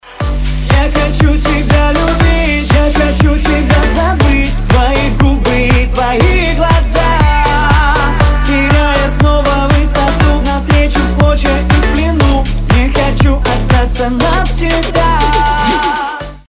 • Качество: 256, Stereo
мужской вокал
dance
club
vocal